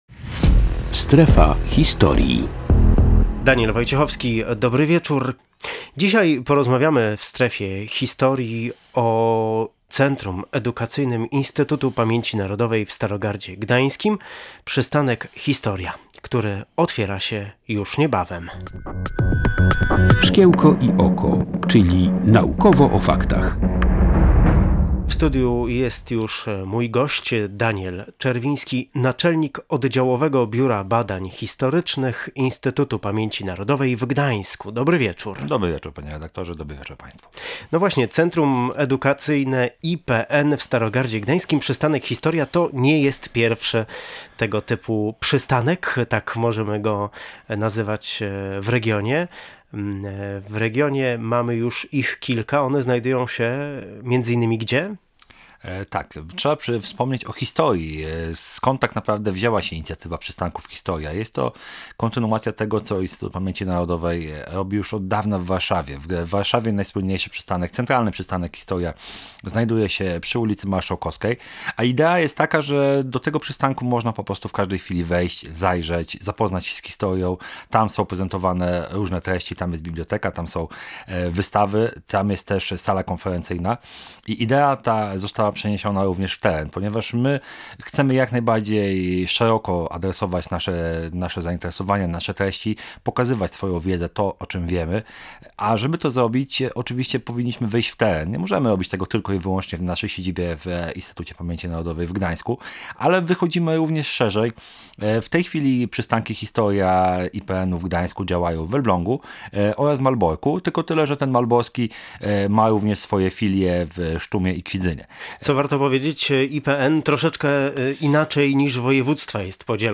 rozmawiał o tej inicjatywie Instytutu Pamięci Narodowej